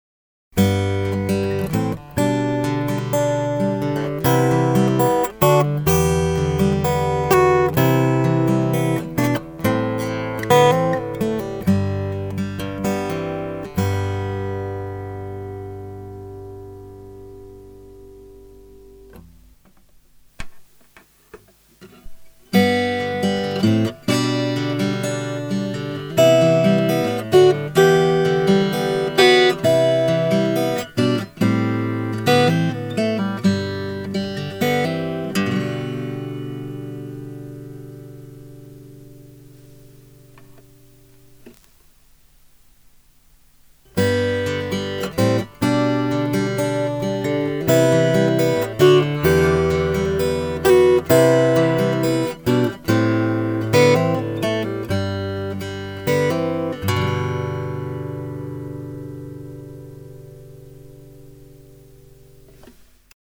MIXの割合は、ECM：Piezo=６：４位です。
まずは、ノンエフェクトでそれぞれの音を聴いてみましょう。続けて演奏しています。１番目がコンタクト型のPiezo。２番目がECM、３番目がMIXしたものです。
◆ピエゾ／ECM／MIXの比較（MP3:約1486KB）